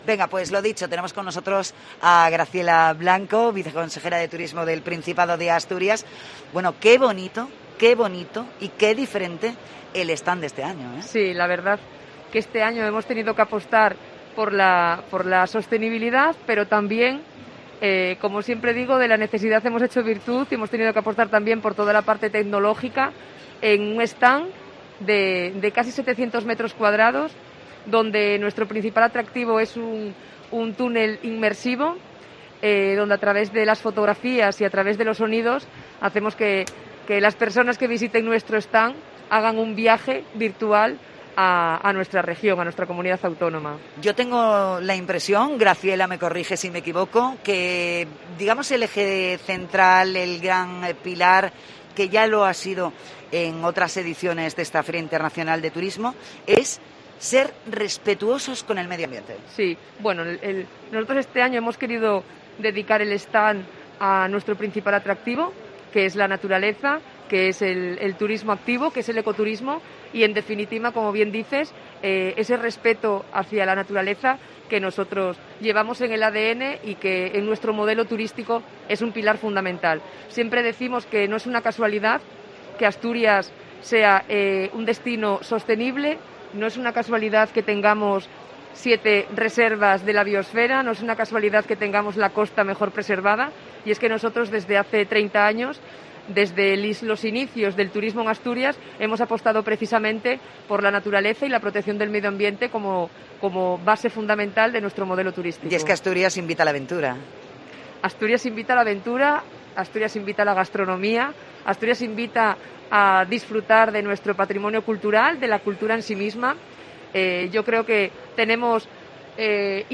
Entrevista a la viceconsejera de Turismo del Principado, Graciela Blanco
"Asturias volverá a ser referencia turística este verano". Así de contundente se ha manifestado la viceconsejera de Turismo del Principado de Asturias, Graciela Blanco, en el programa especial que COPE Asturias ha emitido, este jueves, desde la Feria Internacional de Turismo (FITUR).